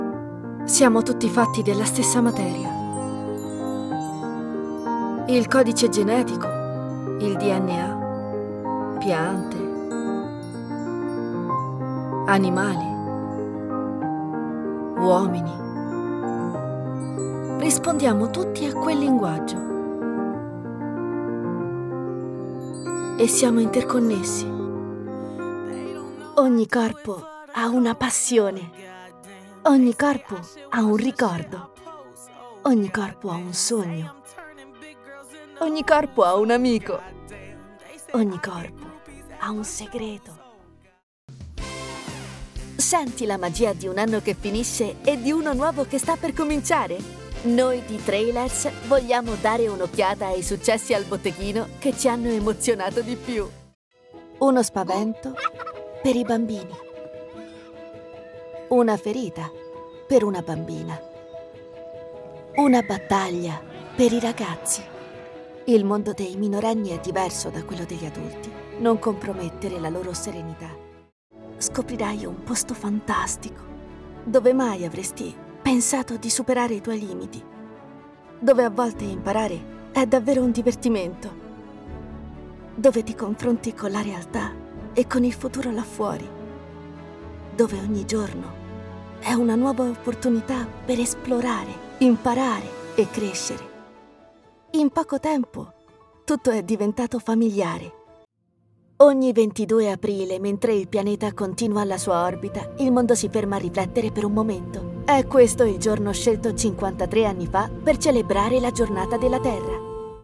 Female
Yng Adult (18-29), Adult (30-50)
My voice carries a natural warmth and energy that resonates with a youthful and fresh tone. It's adaptable, effortlessly shifting between playful lightness and profound emotion, depending on the story I need to tell.
Studio Quality Sample